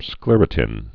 (sklîrə-tĭn, sklĕr-)